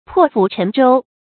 注音：ㄆㄛˋ ㄈㄨˇ ㄔㄣˊ ㄓㄡ
破釜沉舟的讀法